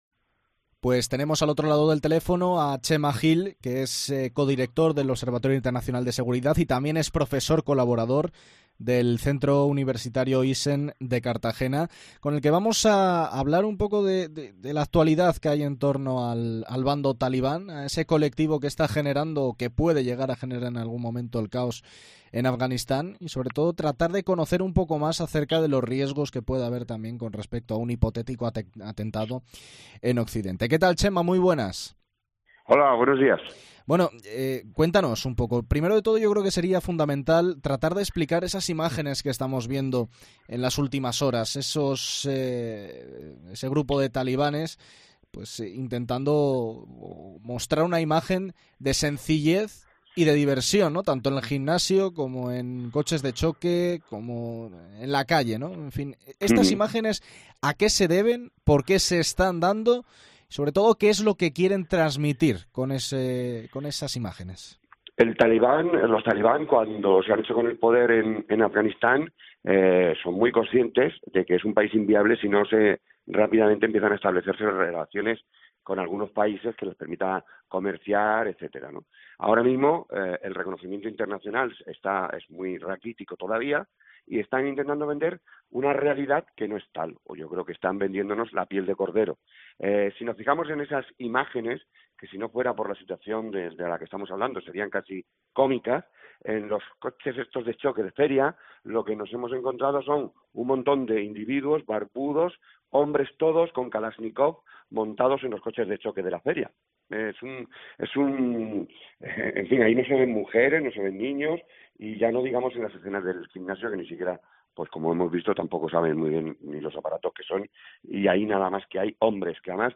y ha pasado por los micrófonos de la Cadena COPE para tratar de encontrar una explicación a lo que está sucediendo.